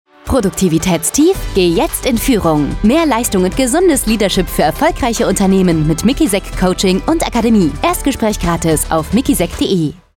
16.06.2025 Ohren auf! MIKISEK Coaching & Akademie ist diese Woche auf Hit Radio FFH.
Funkspot_MIKISEK-Coaching-Akademie-10-Sek-neu.mp3